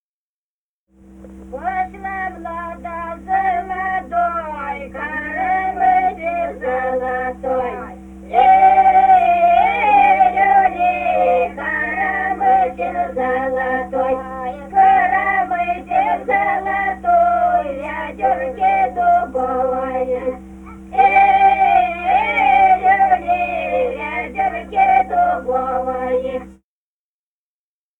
«Пошла млада за водой» (хороводная). с. Денисово Дзержинского района. Пели группа колхозниц